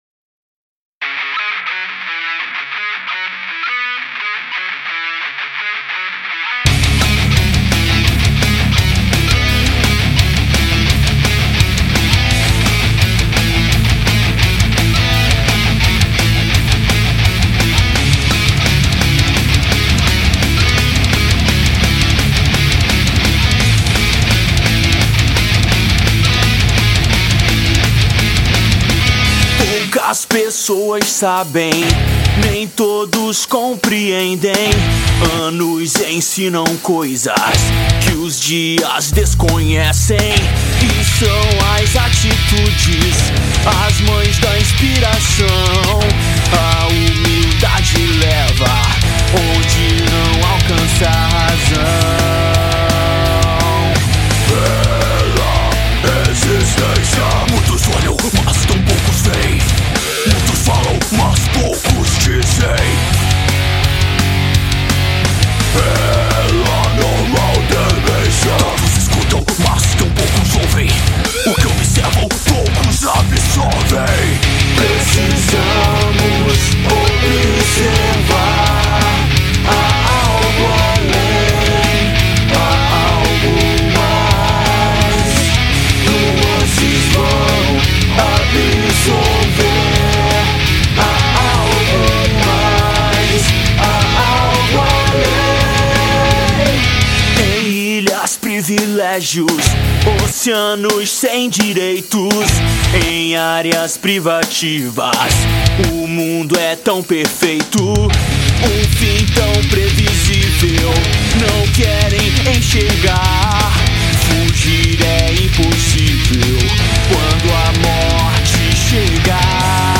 EstiloHeavy Metal